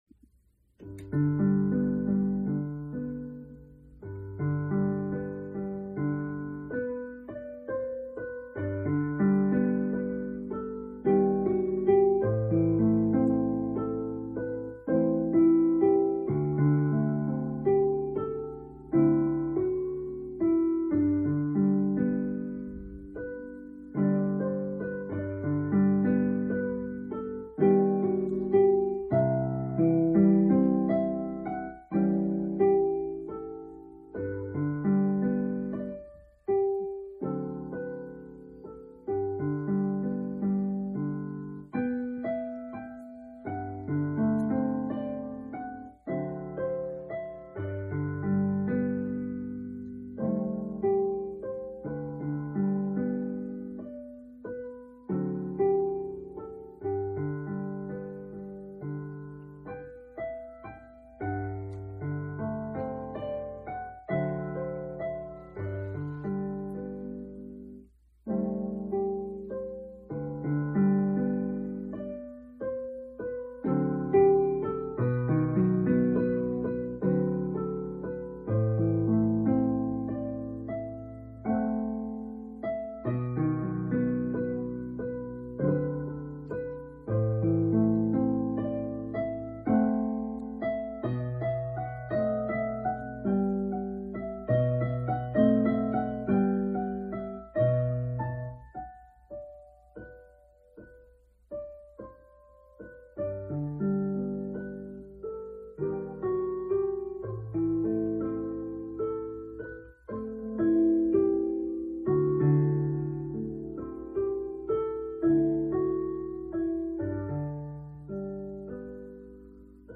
De Crescenzo-mai gyakorlás/esercizio odierno (audio)>>   (Ezennel egy pici hiba került a 2. és a 17. ütembe... / Stavolta ho un piccolo errore nella 2^ e 17^ battuta...)